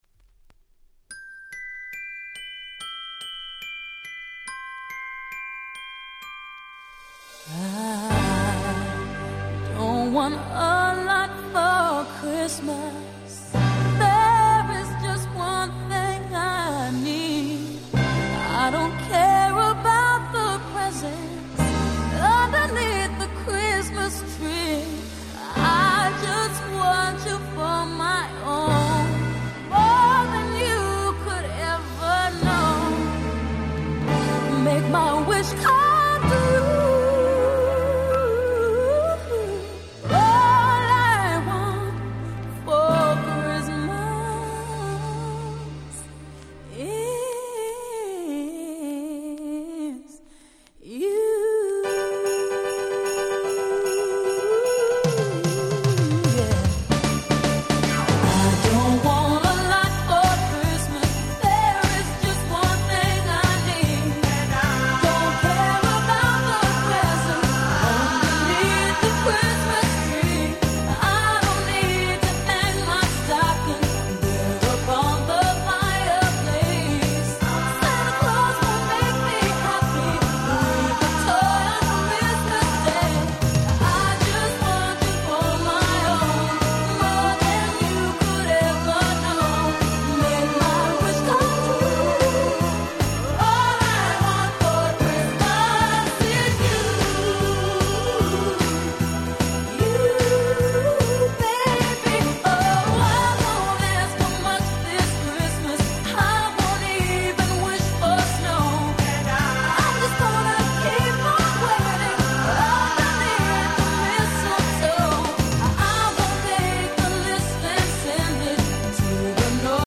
タイトル通り全曲クリスマスソング！！
音質もバッチリ！